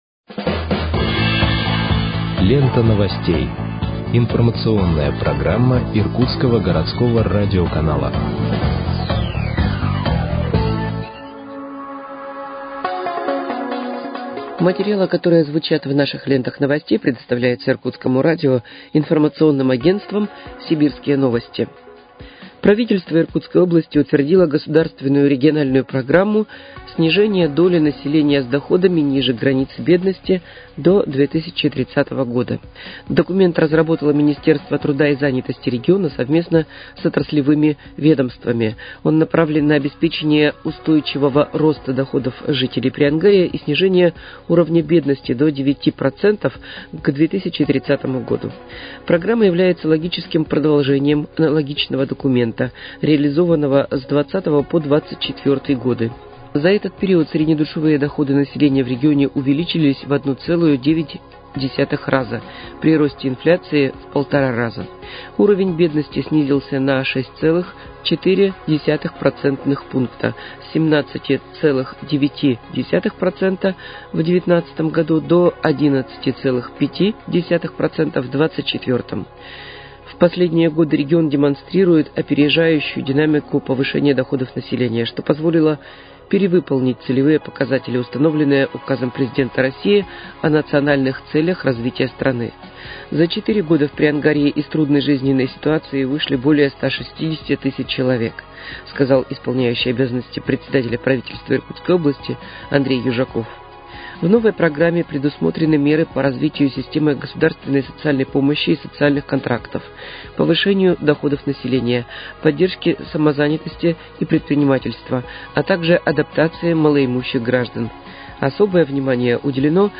Выпуск новостей в подкастах газеты «Иркутск» от 13.10.2025 № 2